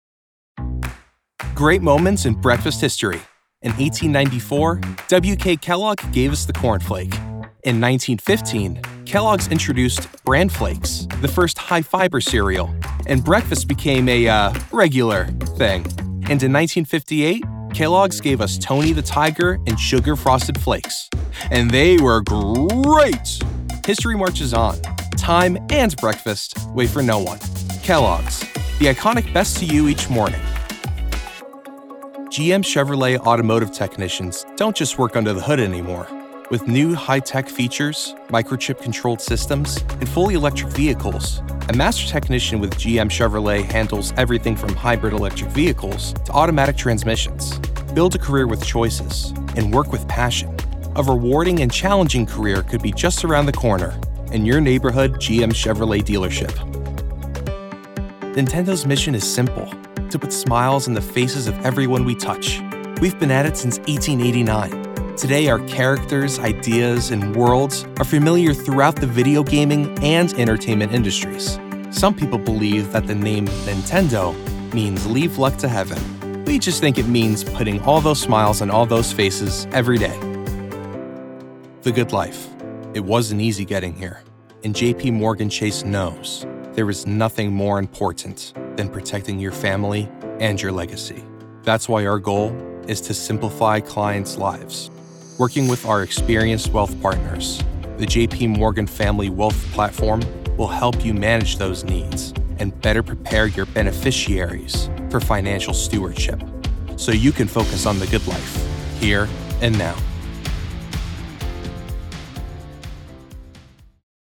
Corporate Narration Demo
New Jersey, General American
Young Adult